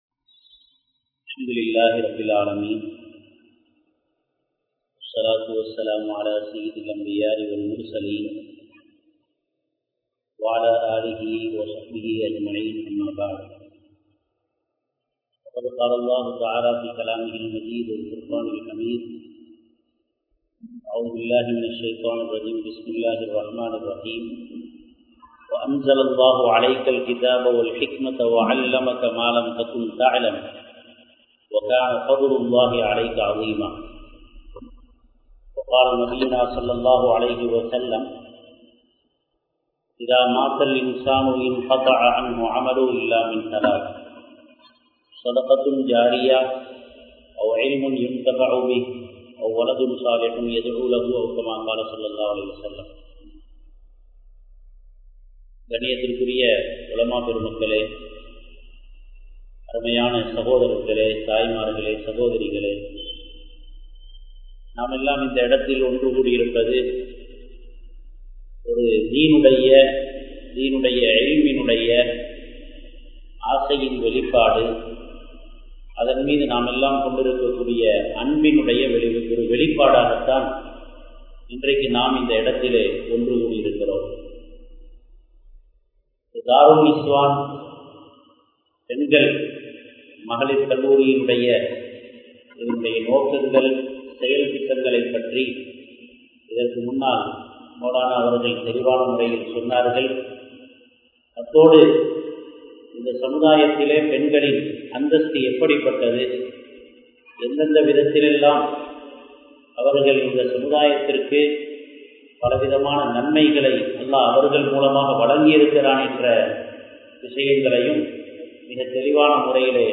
Mun Maathiriyaana Muslim Pengal | Audio Bayans | All Ceylon Muslim Youth Community | Addalaichenai